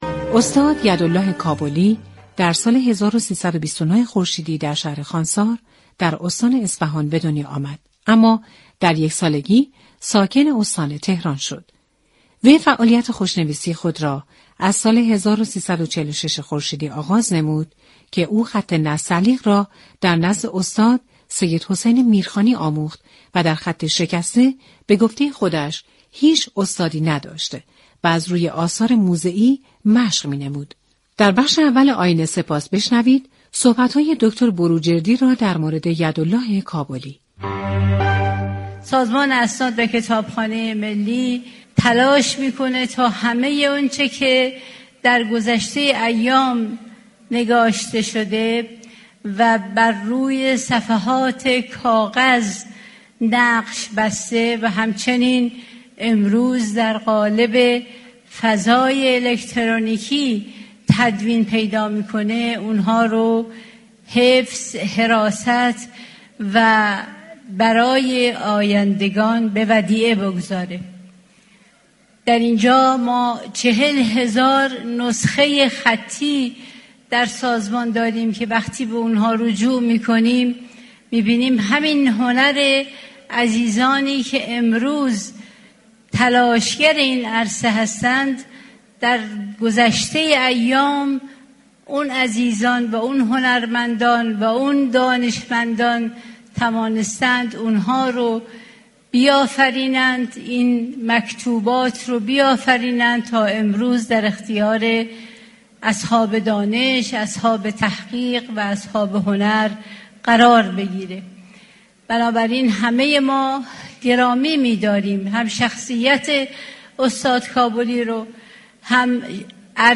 برنامه آیین سپاس این هفته به زندگی و بزرگداشت استاد یداله كابلی هنرمند بزرگ خوشنویس اختصاص داشت ، اشرف بروجردی و سید جلال الدین كزازی درباره استاد كابلی در این برنامه صحبت كردند.